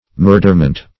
murderment - definition of murderment - synonyms, pronunciation, spelling from Free Dictionary Search Result for " murderment" : The Collaborative International Dictionary of English v.0.48: Murderment \Mur"der*ment\, n. Murder.
murderment.mp3